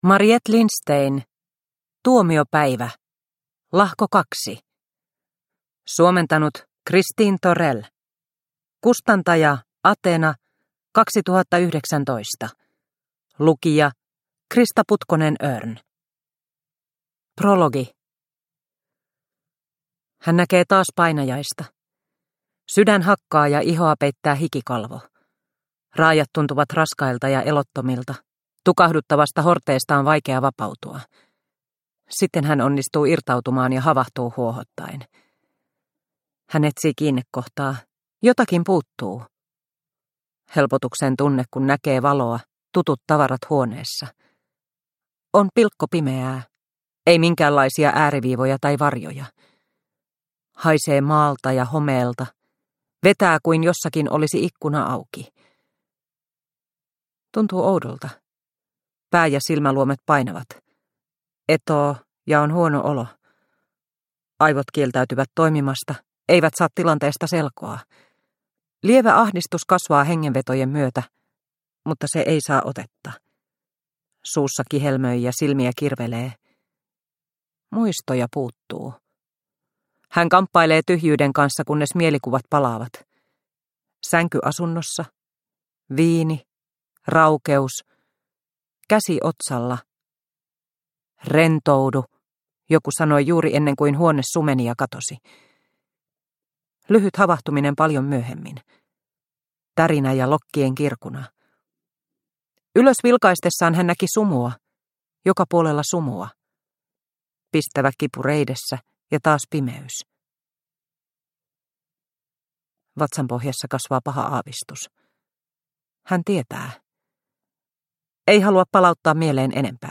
Tuomiopäivä – Ljudbok – Laddas ner